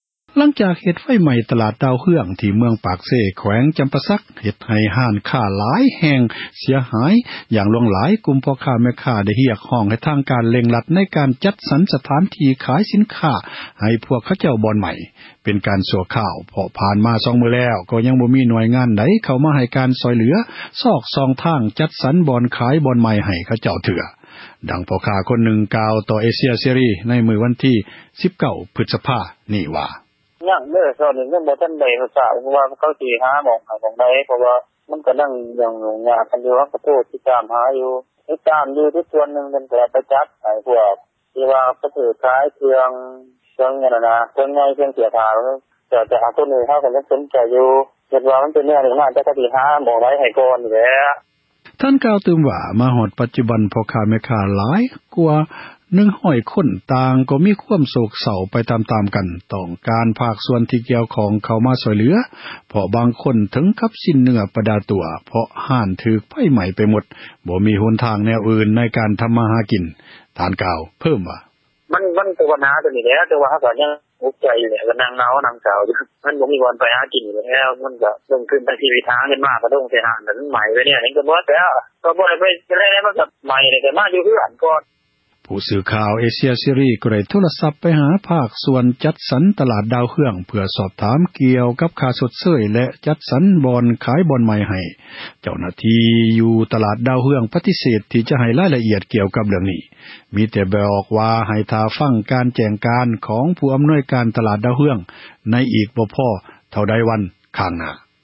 ດັ່ງ ພໍ່ຄ້າຜູ້ນຶ່ງ ກ່າວຕໍ່ ເອເຊັຽເສຣີ ໃນວັນທີ 19 ພຶສພາ ນີ້ວ່າ: